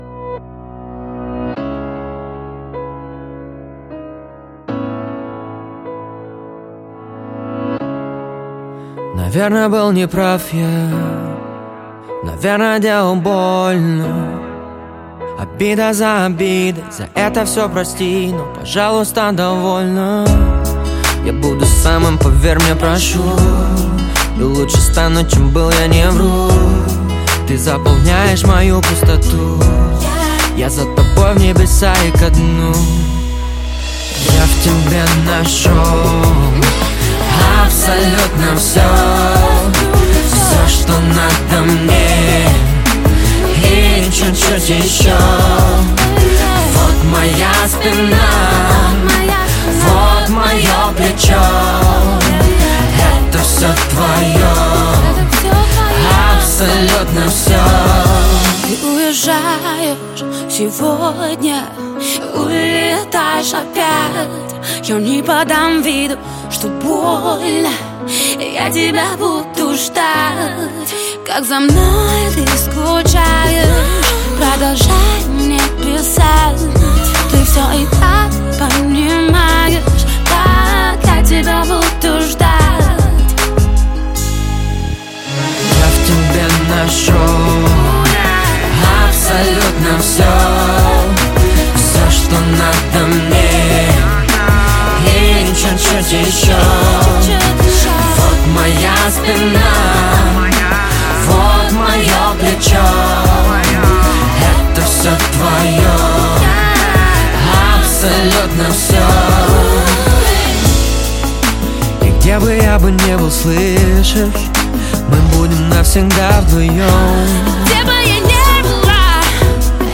Жанр: Русский рэп / R & B